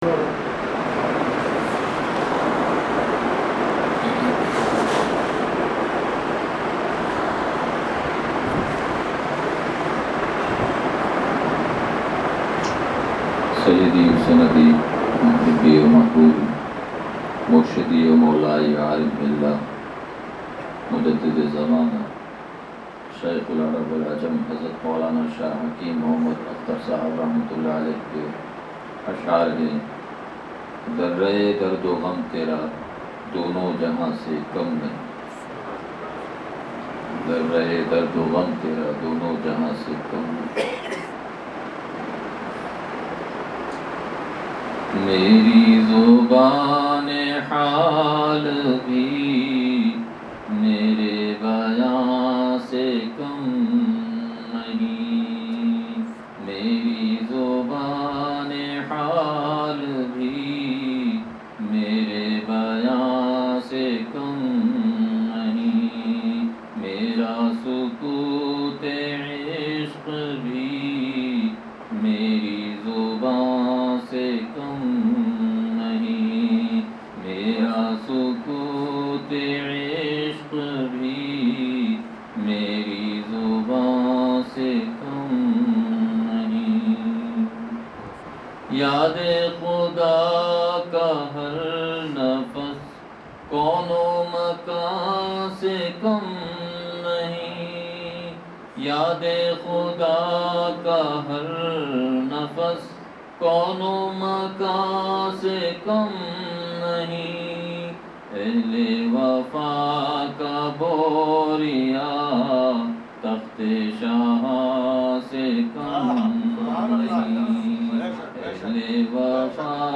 بیان – لانڈھی